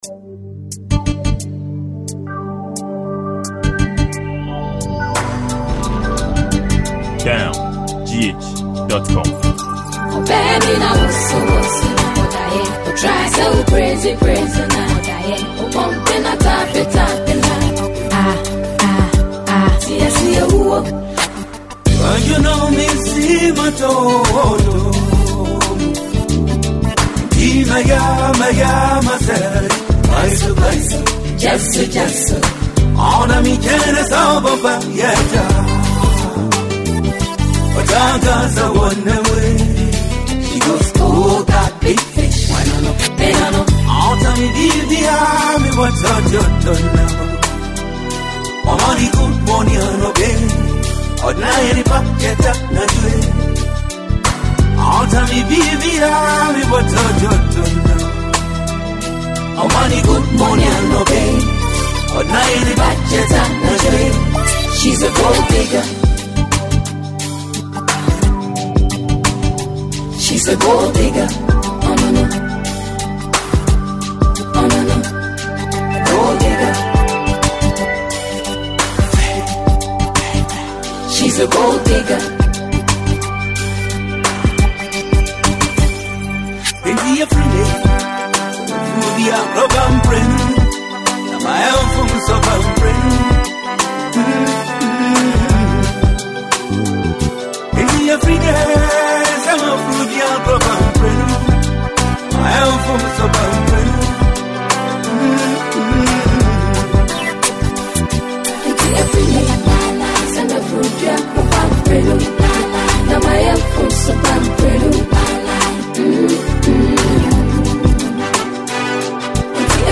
Ghanaian highlife records breaker